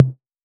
Techno Tom 01.wav